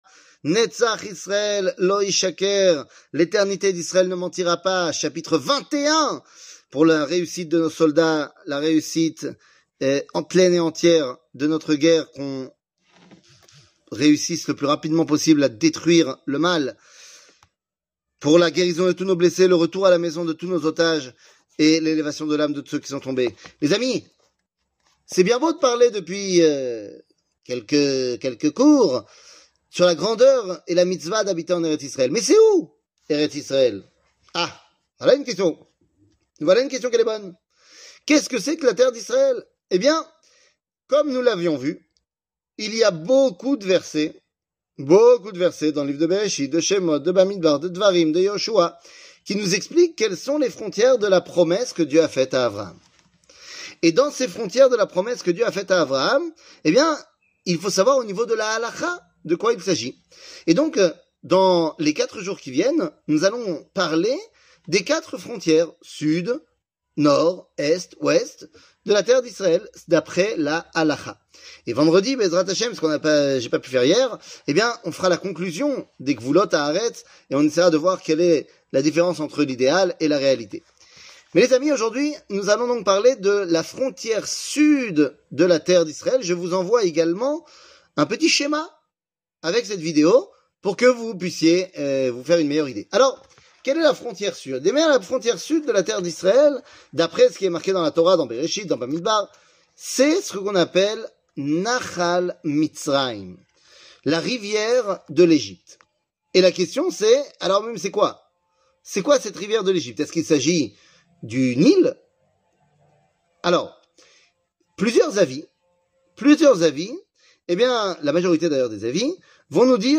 L'éternité d'Israel ne mentira pas ! 21 00:04:31 L'éternité d'Israel ne mentira pas ! 21 שיעור מ 06 נובמבר 2023 04MIN הורדה בקובץ אודיו MP3 (4.12 Mo) הורדה בקובץ וידאו MP4 (6.79 Mo) TAGS : שיעורים קצרים